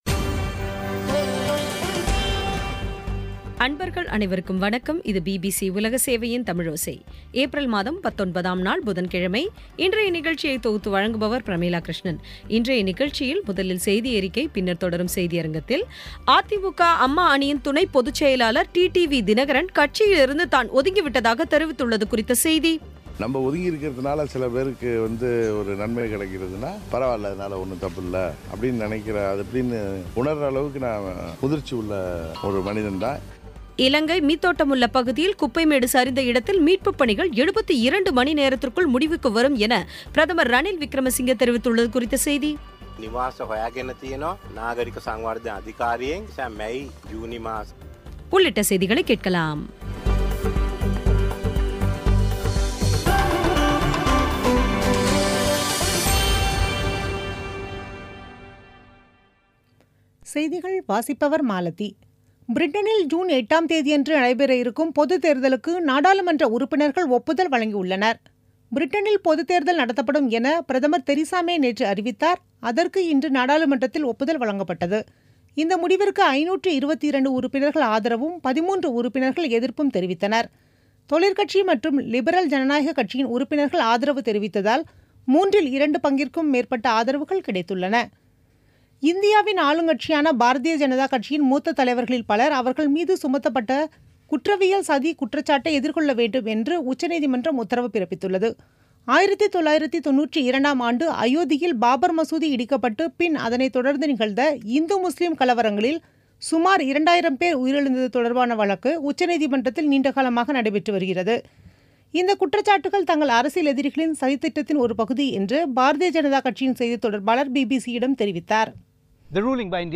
இன்றைய நிகழ்ச்சியில் முதலில் செய்தியறிக்கை, பின்னர் தொடரும் செய்தியரங்கில் அ.தி.மு.க. அம்மா அணியின் துணைப் பொதுச் செயலாளர் டி.டி.வி. தினகரன் கட்சியிலிருந்து தான் ஒதுங்கிவிட்டதாக தெரிவித்துள்ளது குறித்த செய்தி இலங்கை மீத்தொட்டேமுல்ல பகுதியில் குப்பை மேடு சரிந்த இடத்தில் மீட்புபணிகள் 72 மணிநேரத்திற்குள் முடிவுக்குவரும் என பிரதமர் ரணில் விக்கிரமசிங்க தெரிவித்துள்ளது குறித்த செய்தி உள்ளிட்டவை கேட்கலாம்